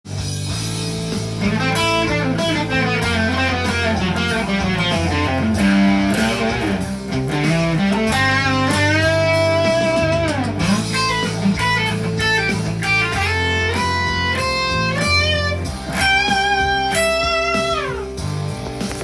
そのフレーズをkey変換しとりあえずはAmで弾けるように練習します。